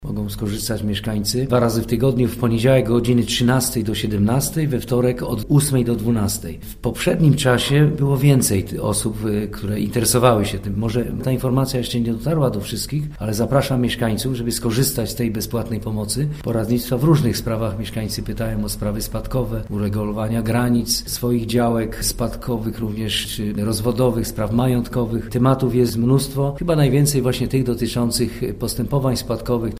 – Niestety, mam wrażenie, ze zbyt mało osób wie, iż może skorzystać z bezpłatnej porady prawnej – informuje Jerzy Fabiś, burmistrz Kargowej.